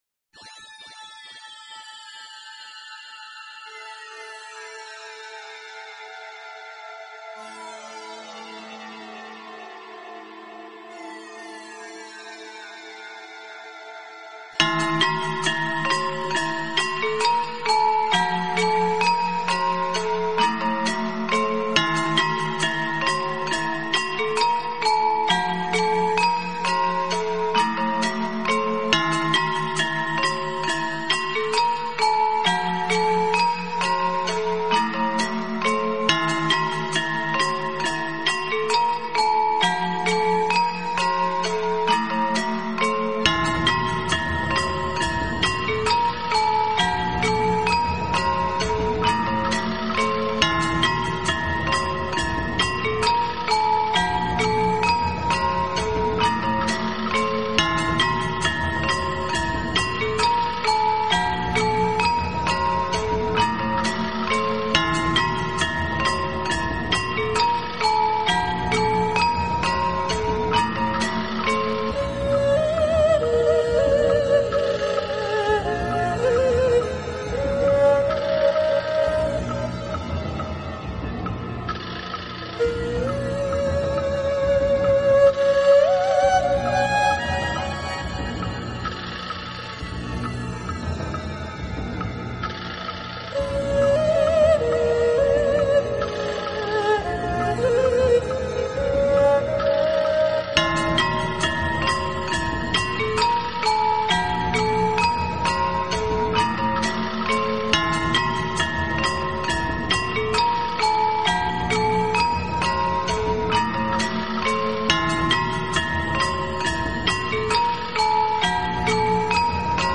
专辑类型: Lo-Fi
试听曲是中国风格的，不知作曲家想